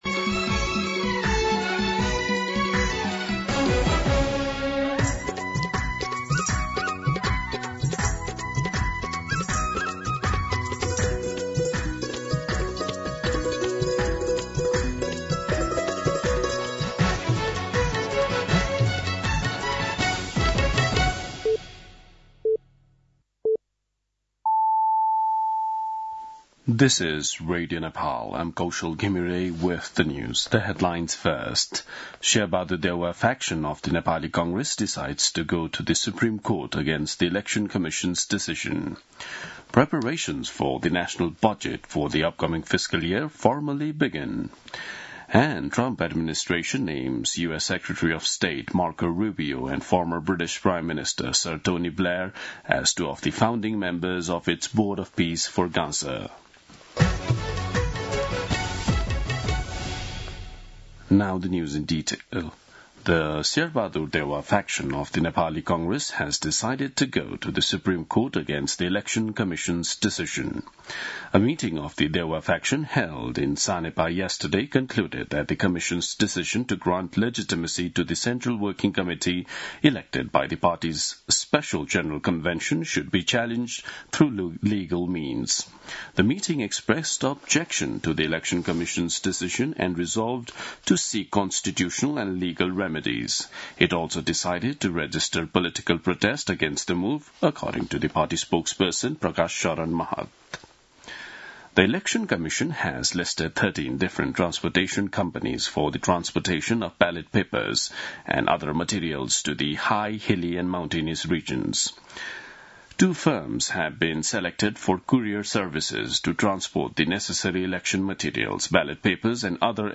दिउँसो २ बजेको अङ्ग्रेजी समाचार : ३ माघ , २०८२
2pm-English-News-2.mp3